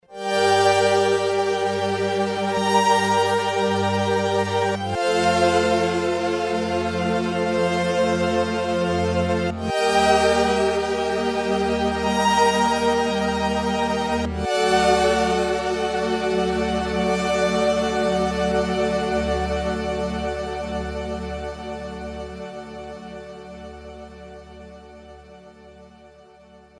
Disk SL-515 "Glass Bell"